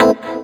Synths
ED Synths 02.wav